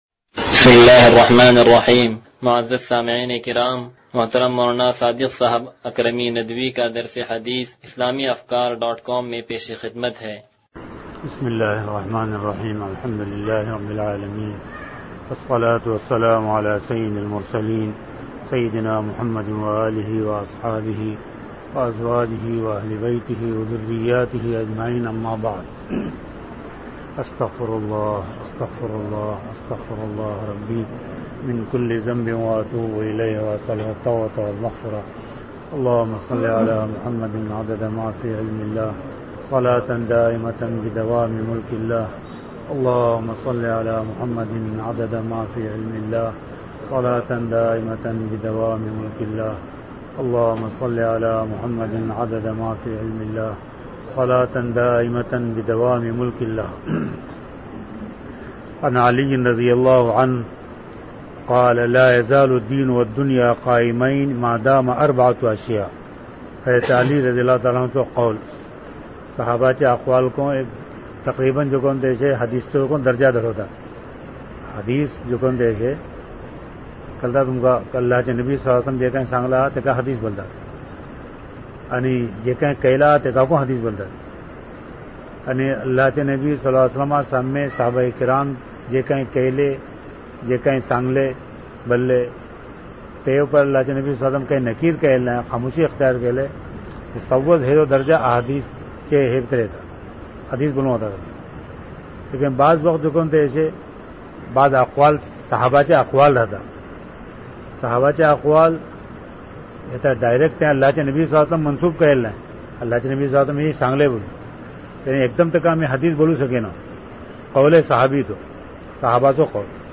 درس حدیث نمبر 0085